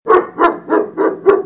Le chien | Université populaire de la biosphère
il aboie
chien_10.mp3